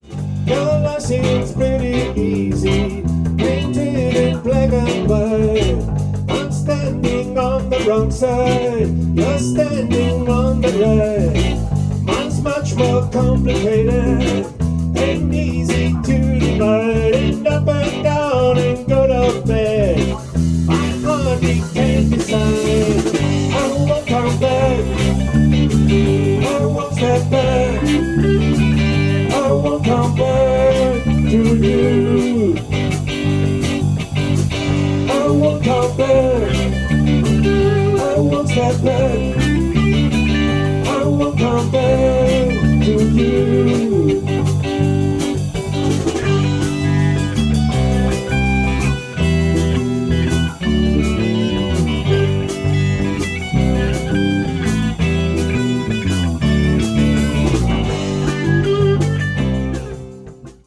Rock, Blues und Soul Band